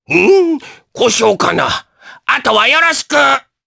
Oddy-Vox_Skill6_jp.wav